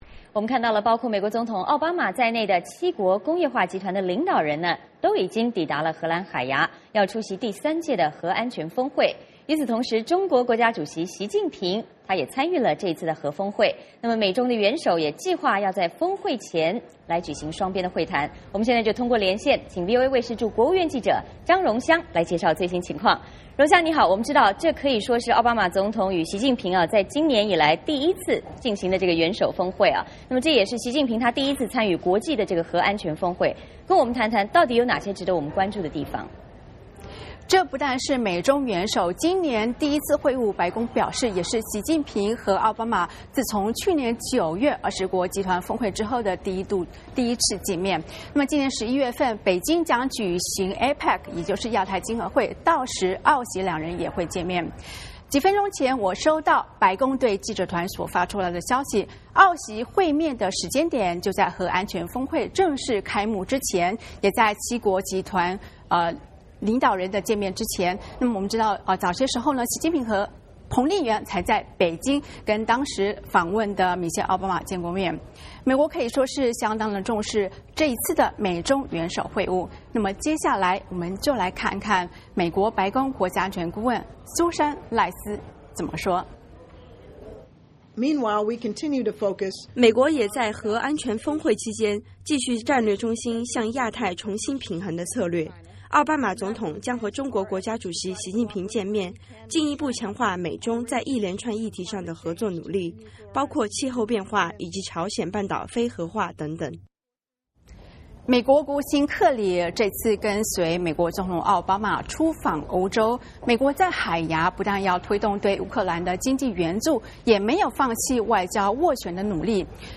嵌入 VOA连线：核安全峰会海牙登场 奥习今年首度会晤 嵌入 代码已经复制到剪贴板。